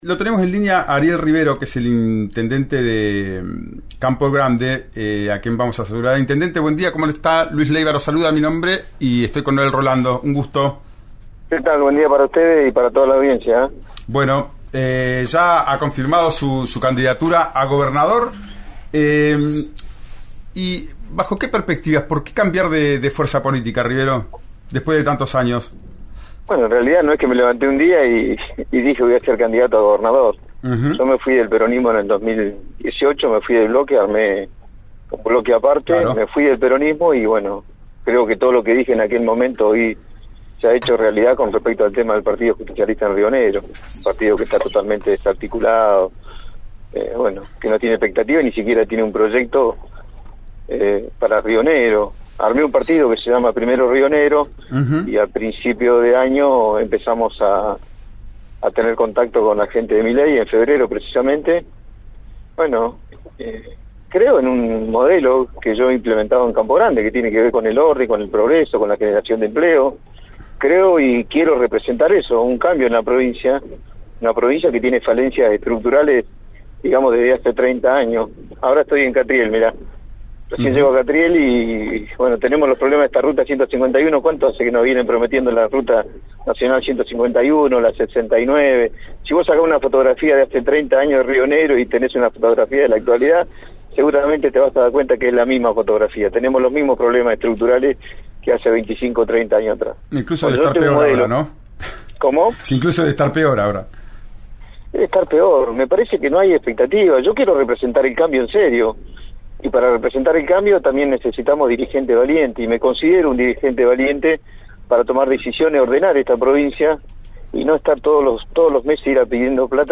En una entrevista con RN RADIO, el exdirigente del PJ Ariel Rivero, cargó sobre el oficialismo provincial y en especial contra el senador Alberto Weretilneck.